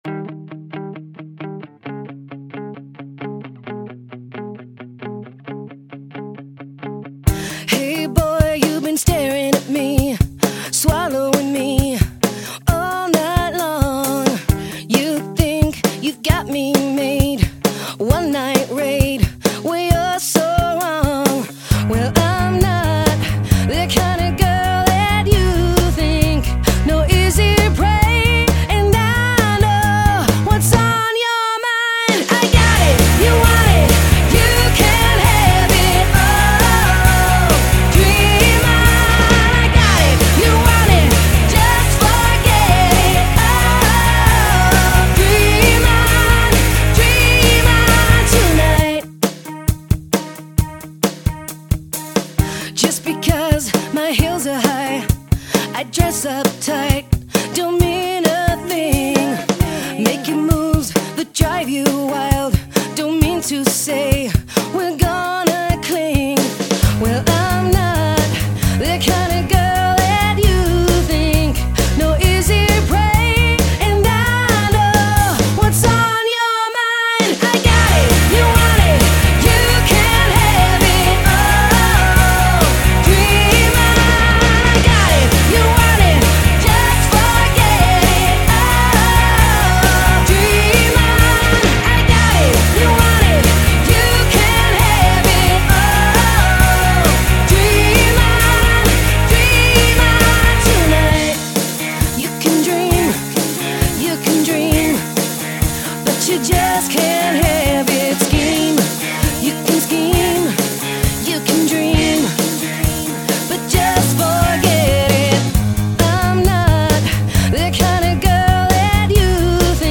Up Tempo Fm vx/guitars/drums/keys